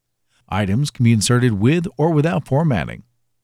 Verzerrung in Profisprecheraufnahme
Hallo Recorder, hört ihr auch die Verzerrung in beiliegender Sprecheraufnahme, oder hab ich Halluzinationen?
Der Sprecher meint, er hätte alles gecheckt und kein Problem gefunden.